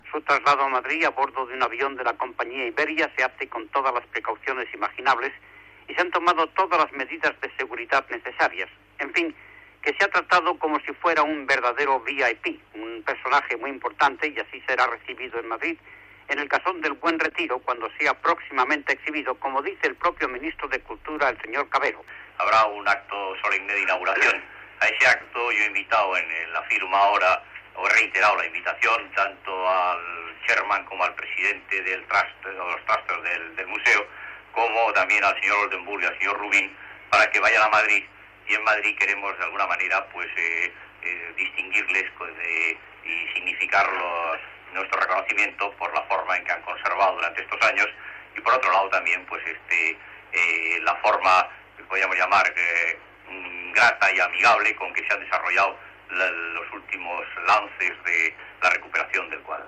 Informació del transport en avió del quadre, des de Nova York. Declaració del ministre Cavero.
Informatiu